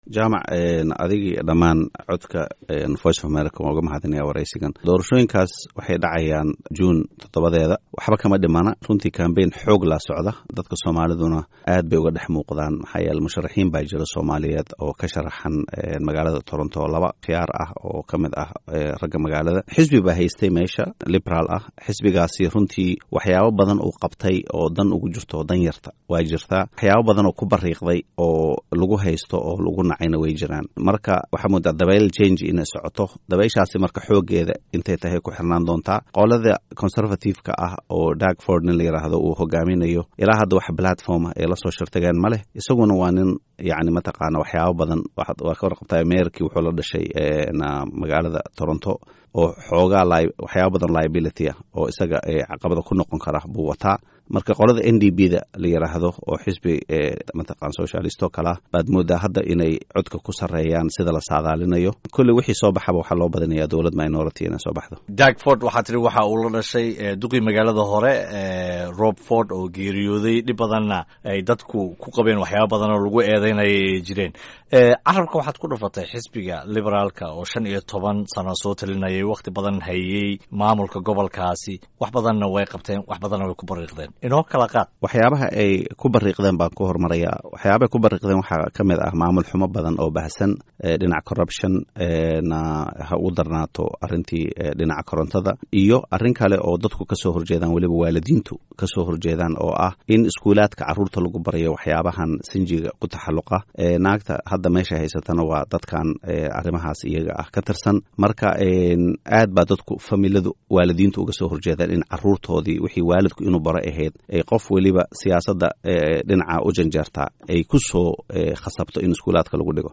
Wareysi: Doorashada Ka Dhaceysa Canada